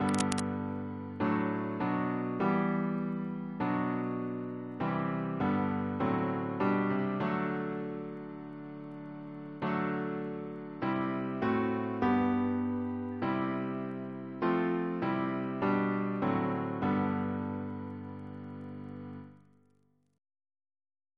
Double chant in G Composer: William Crotch (1775-1847), First Principal of the Royal Academy of Music Reference psalters: ACP: 53; H1940: 655; H1982: S186 S229